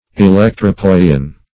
electropoion - definition of electropoion - synonyms, pronunciation, spelling from Free Dictionary
Electropoion \E*lec`tro*poi"on\, n., or Electropoion fluid